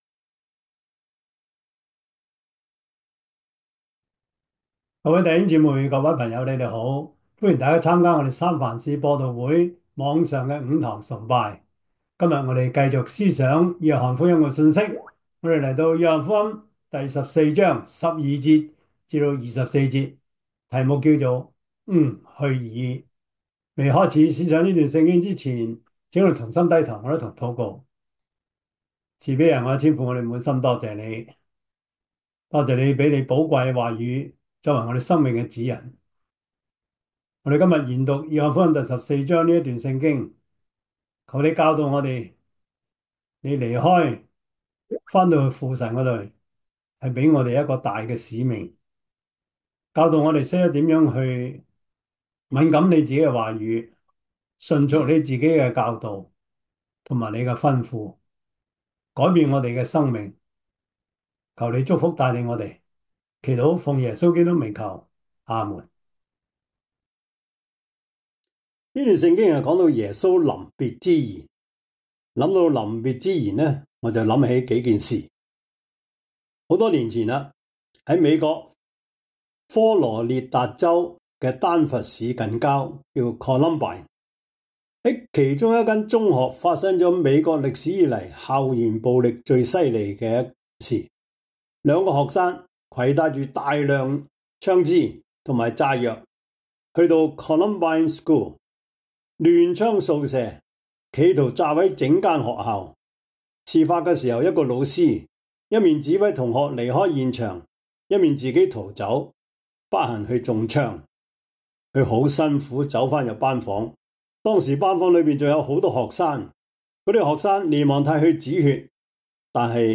約翰福音 14:12-24 Service Type: 主日崇拜 約翰福音 14:12-24 Chinese Union Version
Topics: 主日證道 « 改造人 獻上一首感恩的詩歌 »